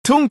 TUNG TUNG TUNG SAHUR Sound Effect.mp3